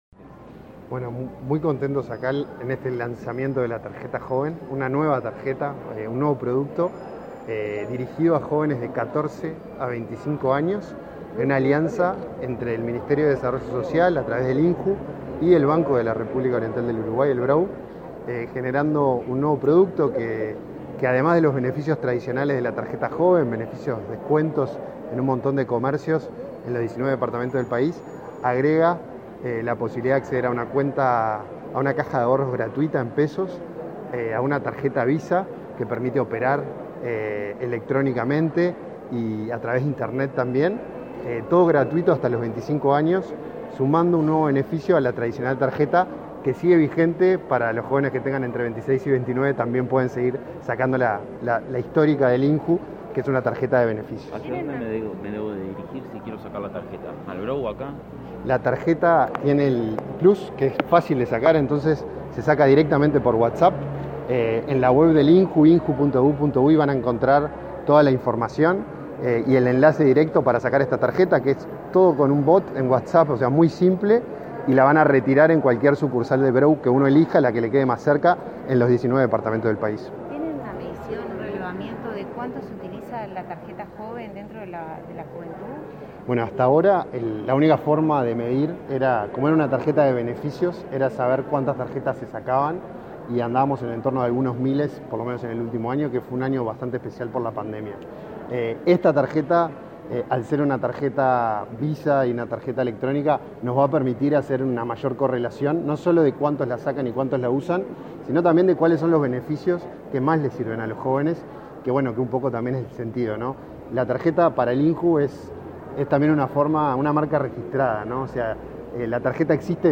Declaraciones a la prensa del director del INJU, Felipe Paullier